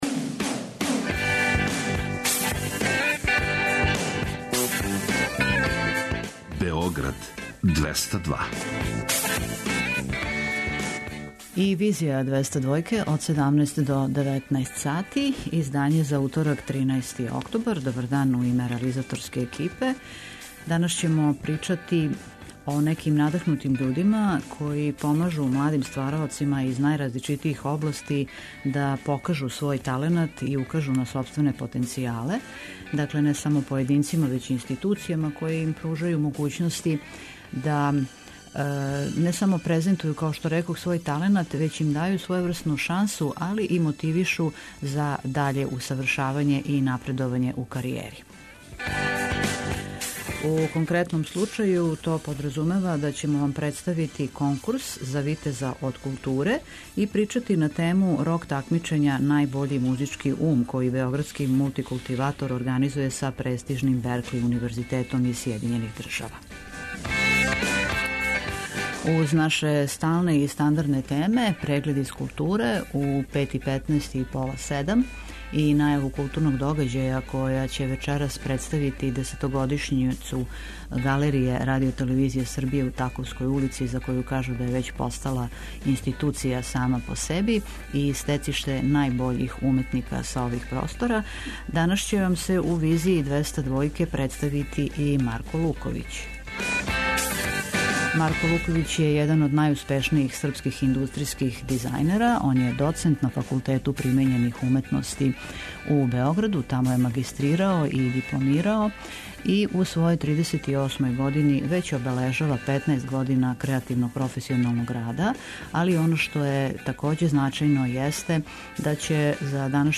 преузми : 56.83 MB Визија Autor: Београд 202 Социо-културолошки магазин, који прати савремене друштвене феномене.